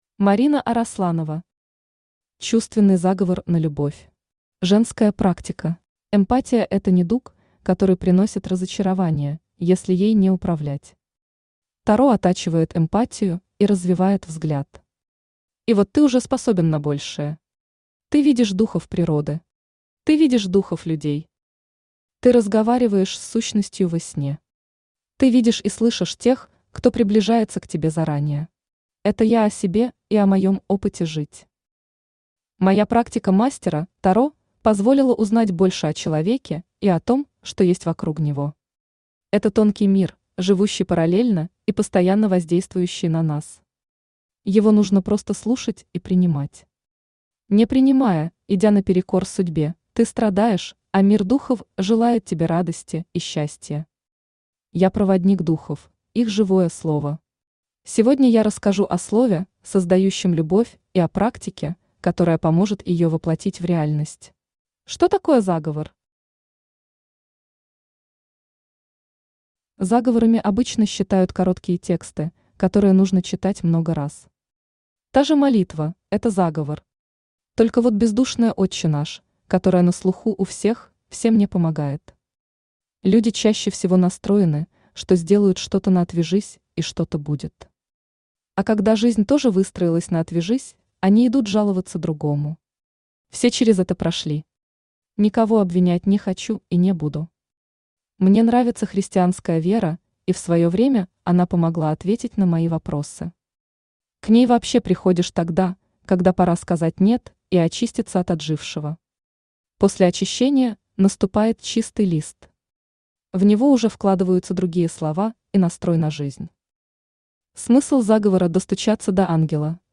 Женская практика Автор Марина Арасланова Читает аудиокнигу Авточтец ЛитРес.